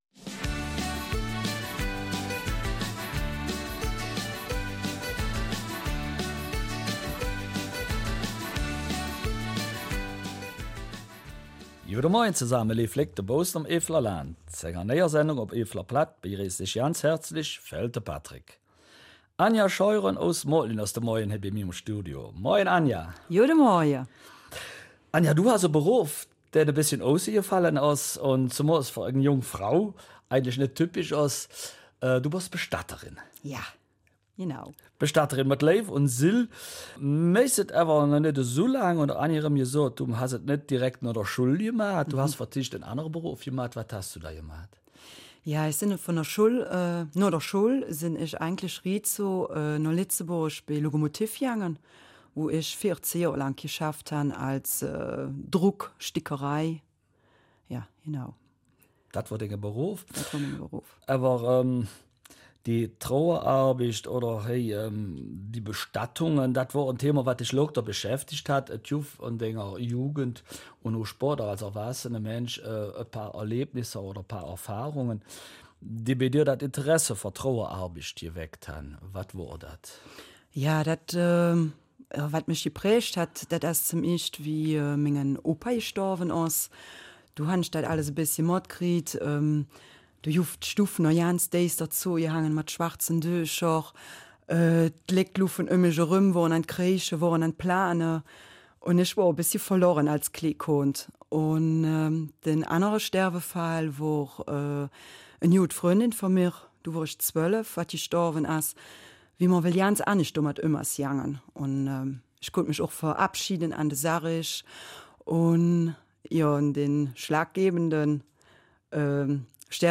Eifeler Mundart: Beruf Bestatterin